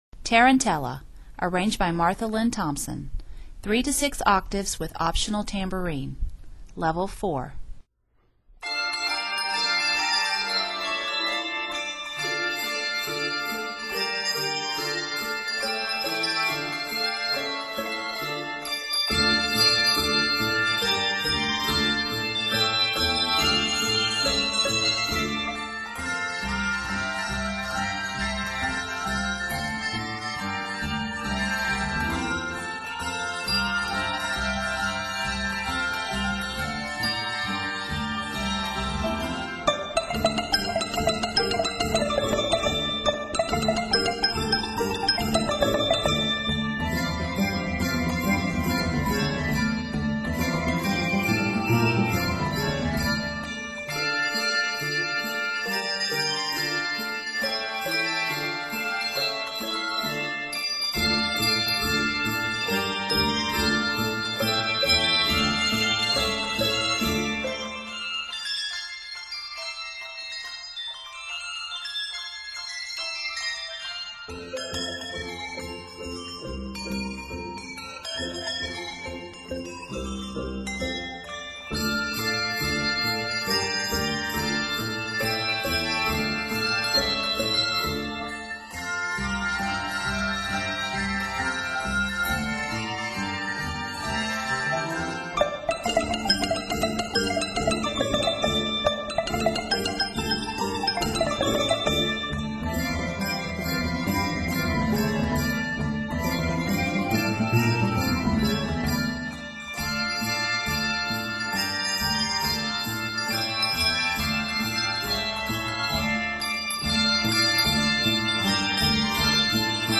The main challenge will be keeping up with the lively tempo.
this toe-tapper is scored in d minor and is 111 measures.